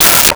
Metal Lid 03
Metal Lid 03.wav